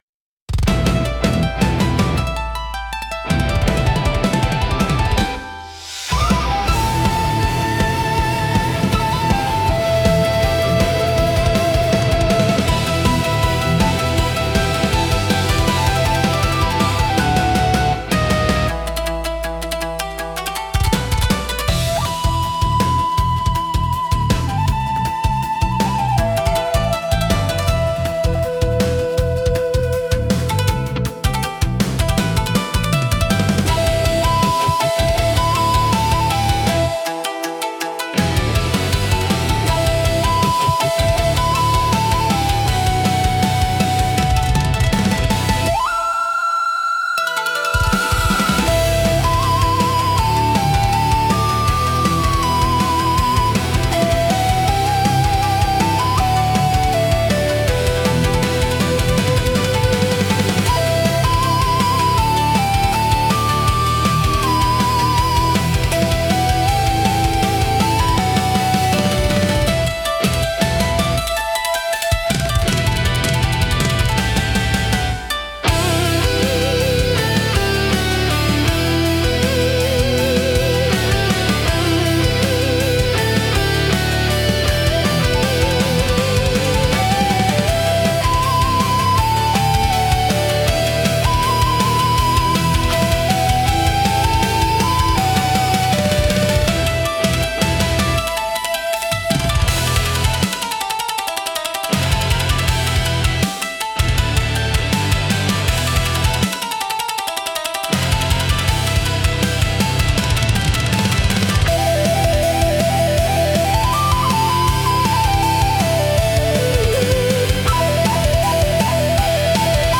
聴く人に力強さと神秘性を同時に感じさせ、日本古来の精神と現代のエネルギーを融合したインパクトを与えます。